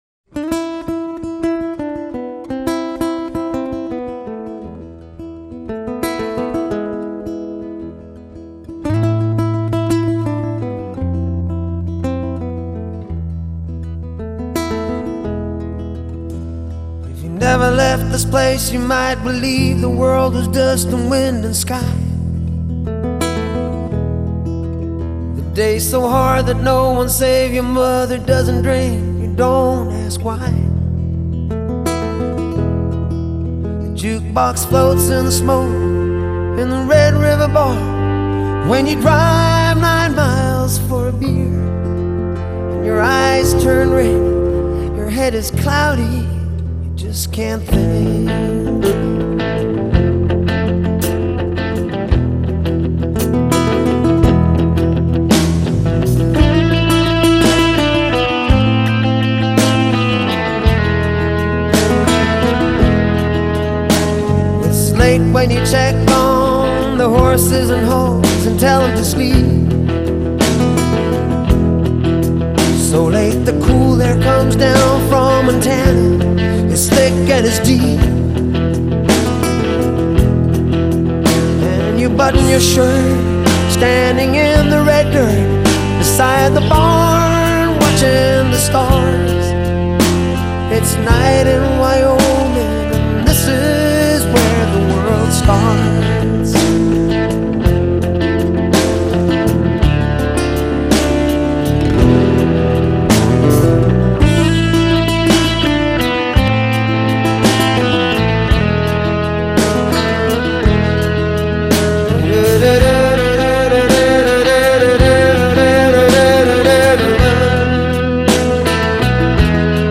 迷人的白色噪音底子隐没成布帘点缀的星光
有着清淡着色的回转，笼罩起薄纱般透明微亮的清晨，一点一点逐渐消失在呼吸的尾梢。